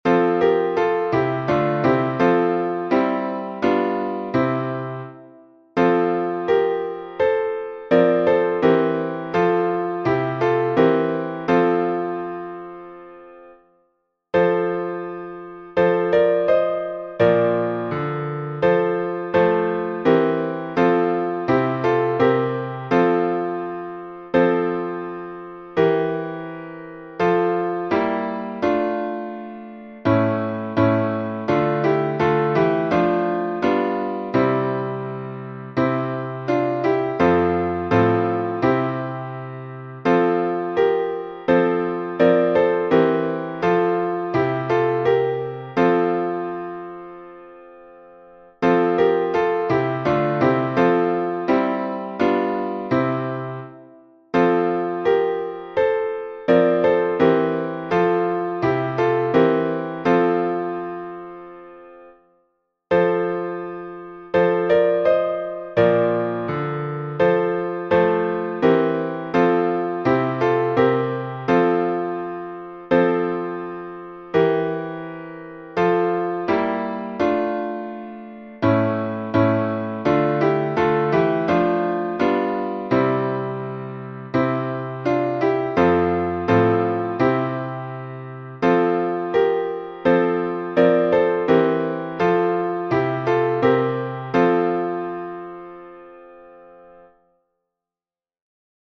греческий распев